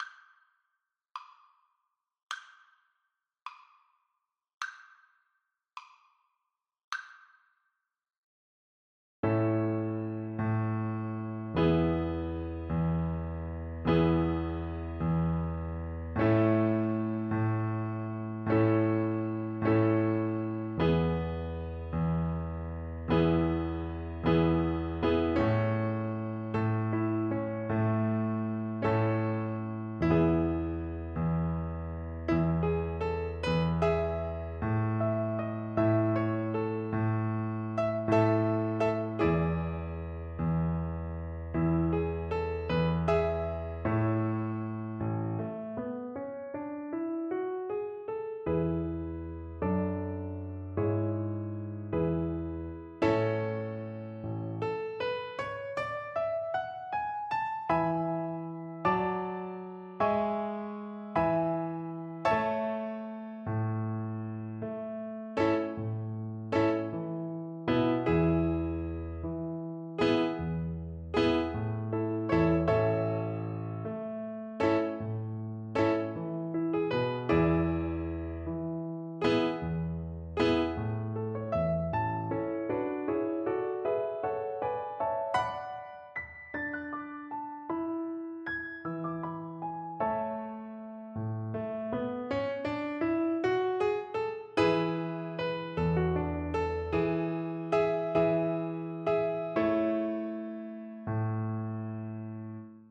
World Europe Portugal Chamarita (Portuguese Traditional)
Violin
Moderato . = c.80
6/8 (View more 6/8 Music)
A major (Sounding Pitch) (View more A major Music for Violin )
Traditional (View more Traditional Violin Music)